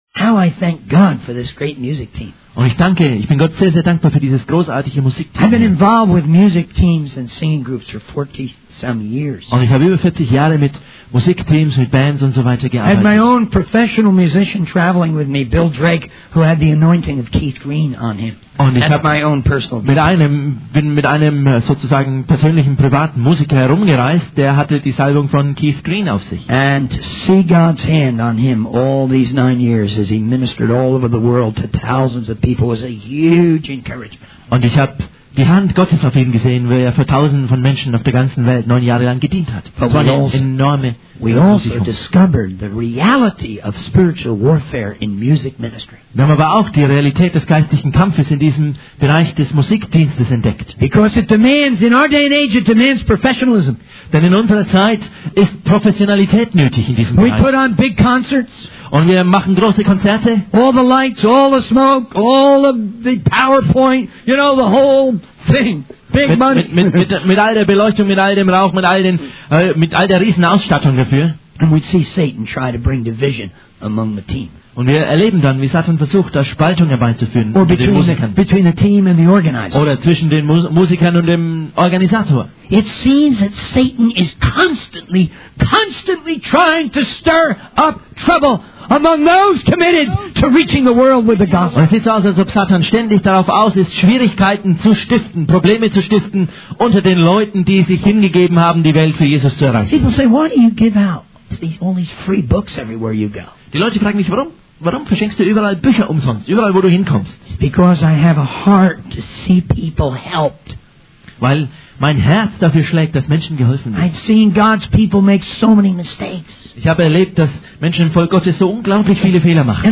In this sermon, the speaker discusses the importance of professionalism in the church and how it is often accompanied by big concerts and elaborate productions. However, the speaker also highlights how Satan tries to bring division among the team and between the team and the organizer.